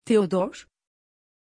Pronuncia di Teodor
pronunciation-teodor-tr.mp3